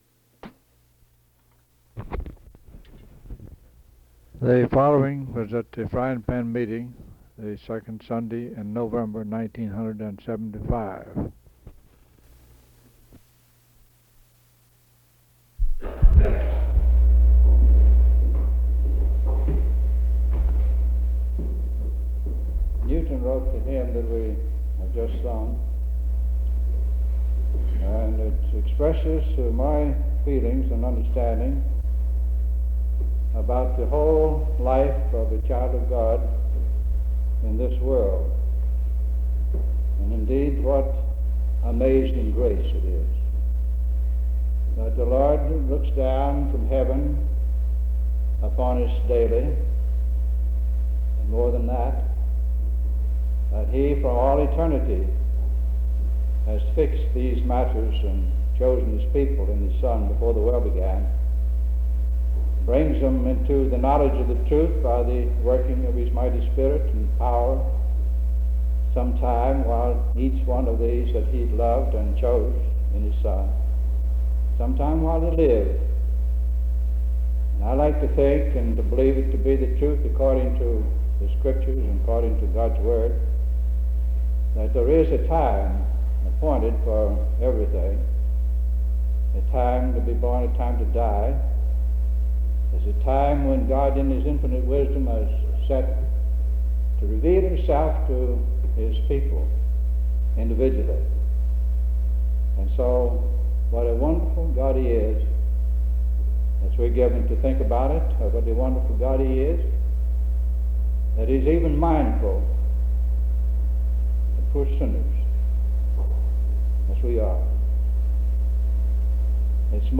sermon collection